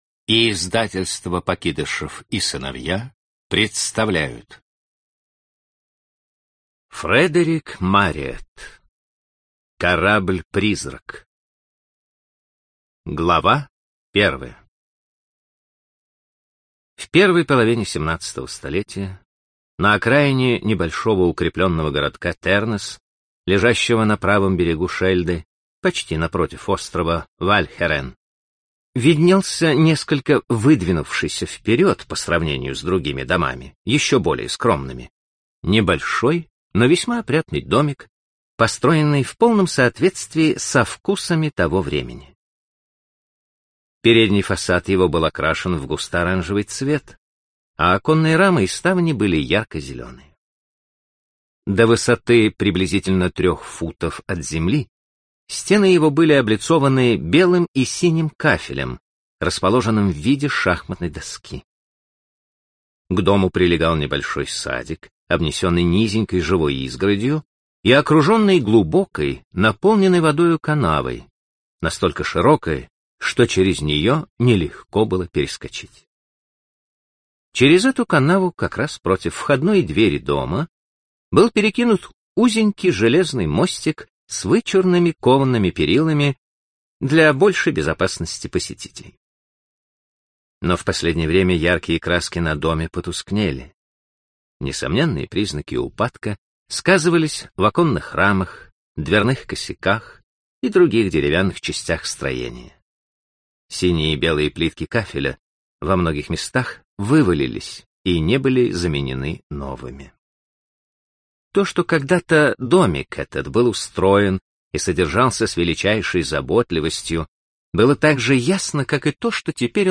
Аудиокнига Корабль-призрак - купить, скачать и слушать онлайн | КнигоПоиск